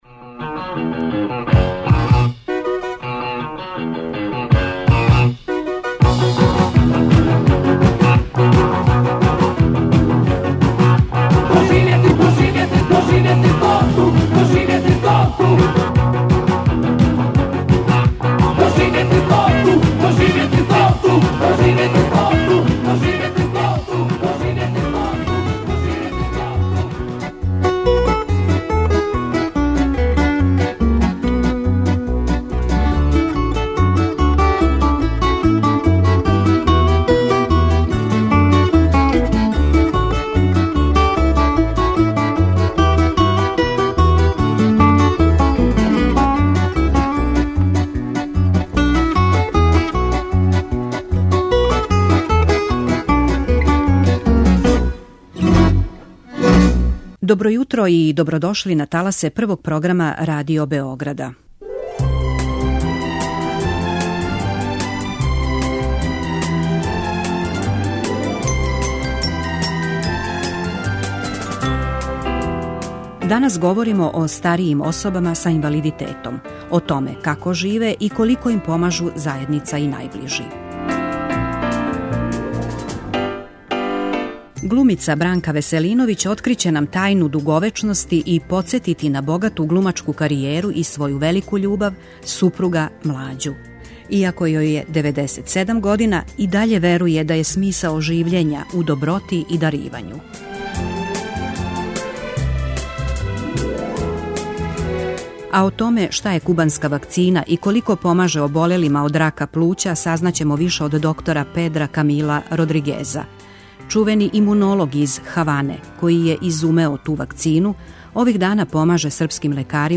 доноси интервјуе и репортаже посвећене старијој популацији